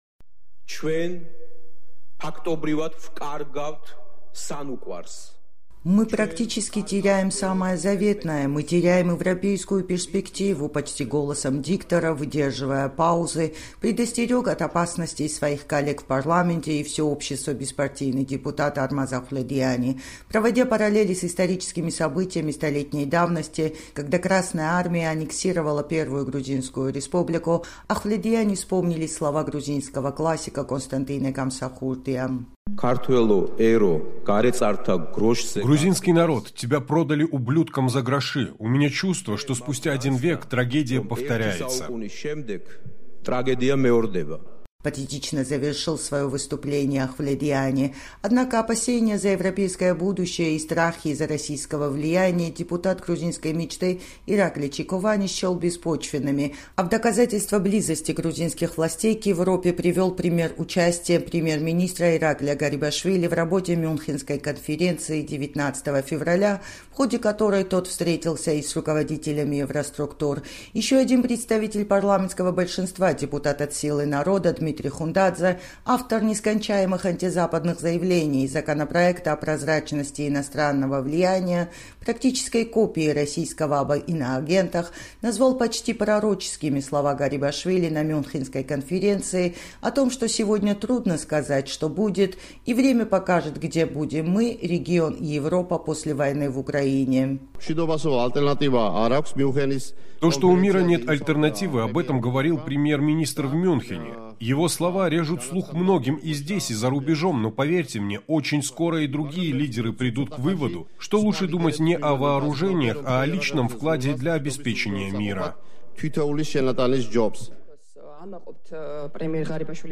В демонстрацию непримиримости и нетерпимости превратился сегодня час политических заявлений на пленарном заседании грузинского парламента.
«Мы практически теряем самое заветное, мы теряем европейскую перспективу», – почти голосом диктора, выдерживая паузы, предостерег от опасностей своих коллег в парламенте и все общество беспартийный депутат Армаз Ахвледиани.
Здесь спикера Папуашвили однопартийцы перебили аплодисментами.